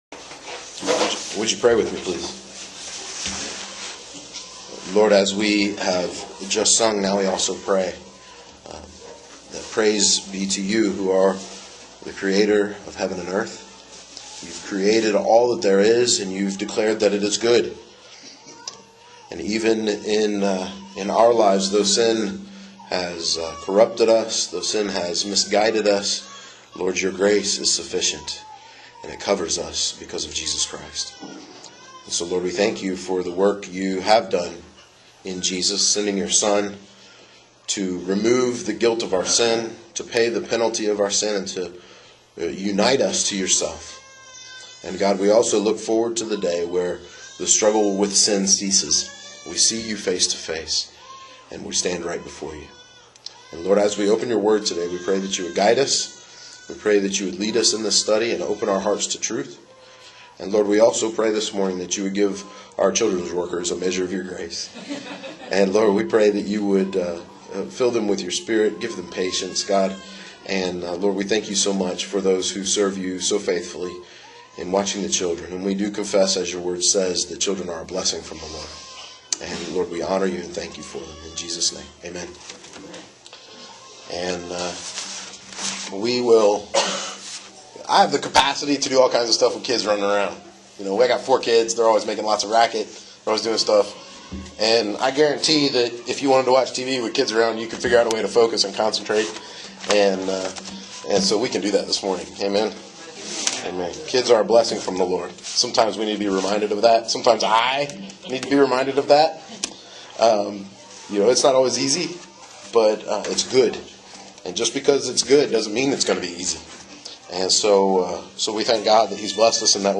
This sermon gives particular attention to the role of Boaz.